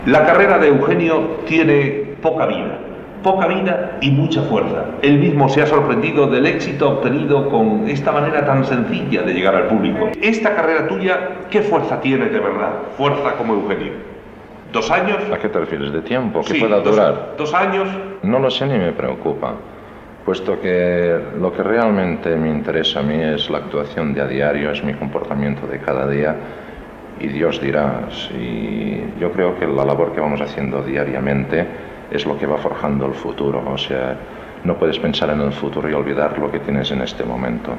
Fragment de l'entrevista a l'humorista Eugenio (Eugenio Jofra)
Entreteniment